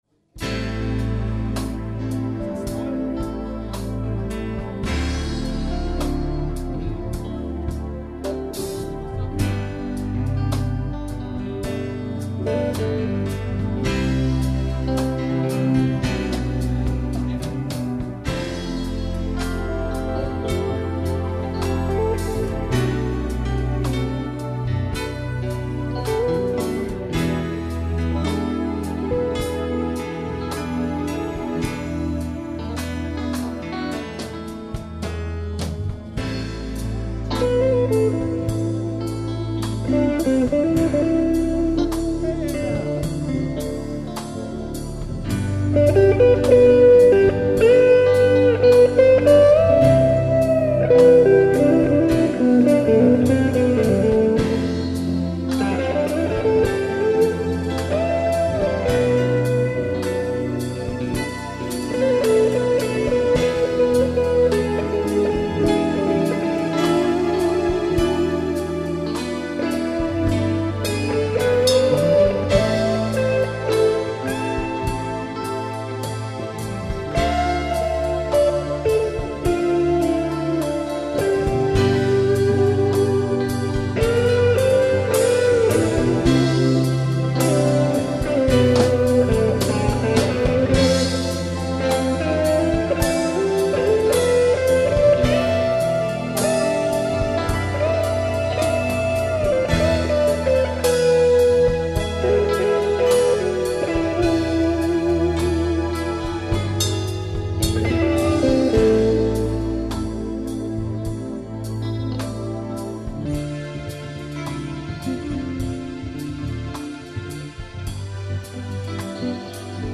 Ballade
(Rickenbacker's 2007-09-04)